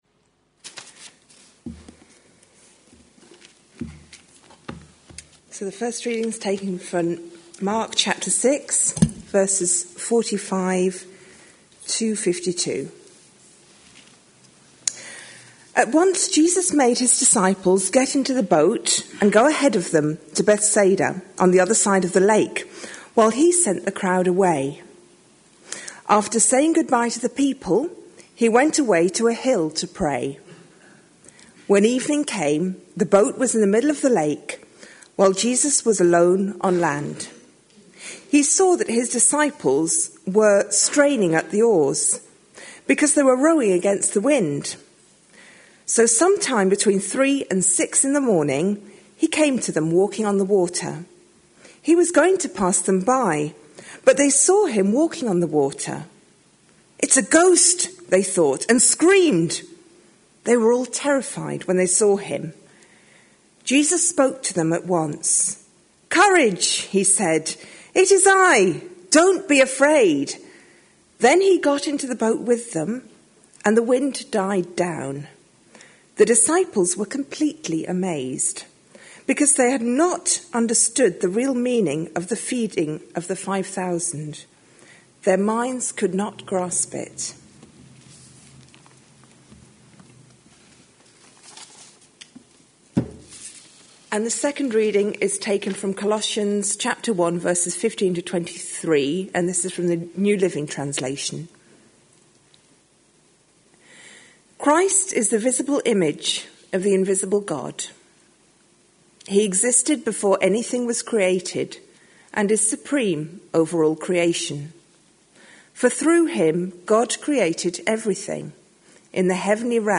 A sermon preached on 23rd March, 2014, as part of our Shaken, Not Stirred series.